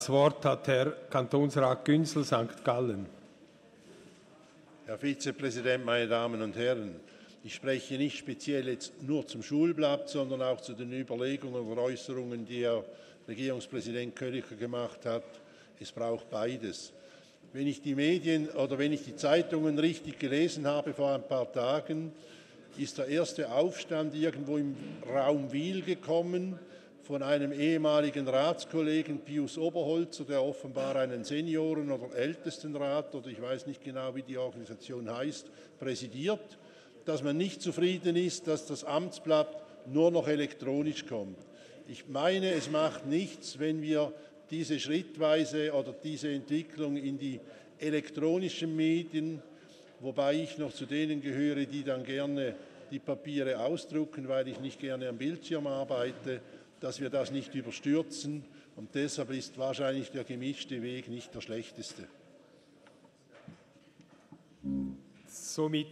23.4.2019Wortmeldung
Ratsvizepräsident:
Session des Kantonsrates vom 23. und 24. April 2019